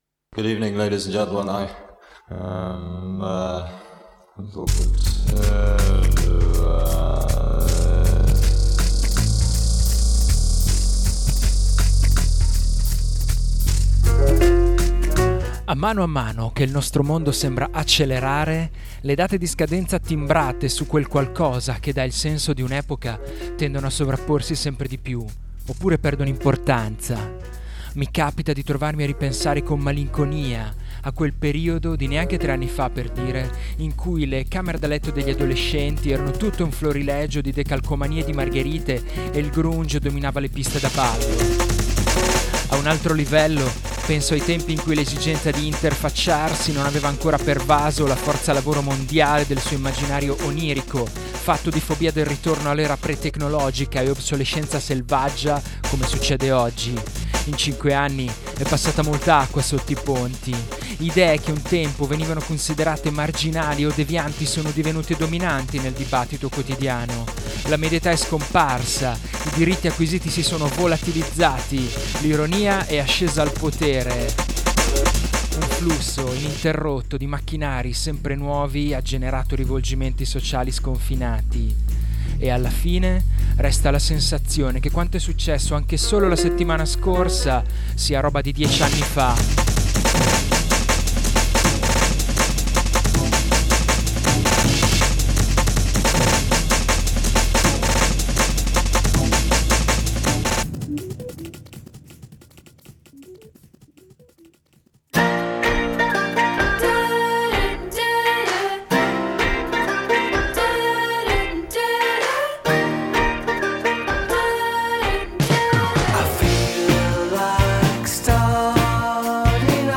Indiepop, indie rock e brindisi!